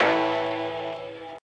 0 Cowbell Phonk Wet Hard